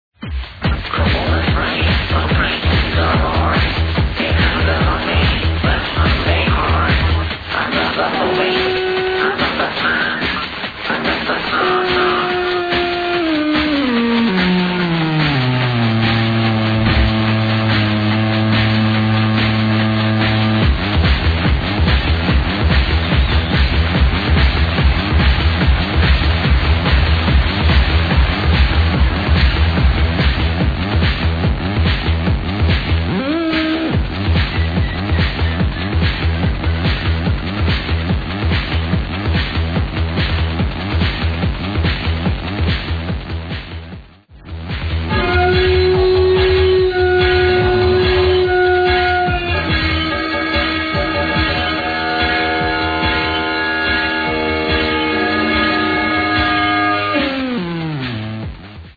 I loved those noisy sounds